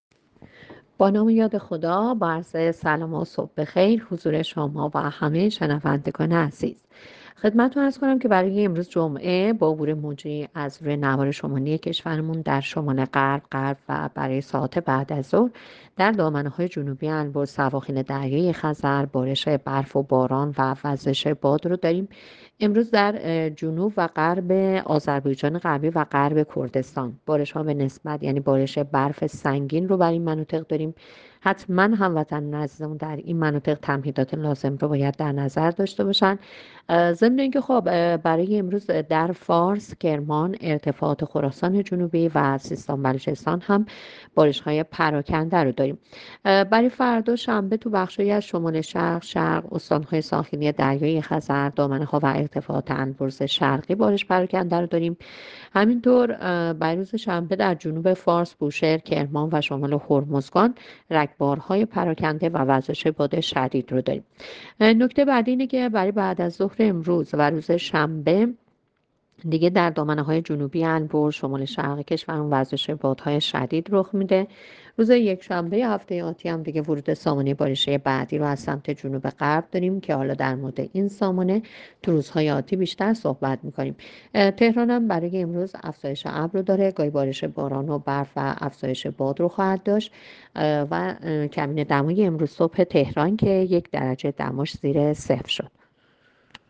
گزارش رادیو اینترنتی پایگاه‌ خبری از آخرین وضعیت آب‌وهوای ۲۶ بهمن؛